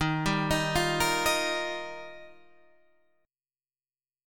D# Major Add 9th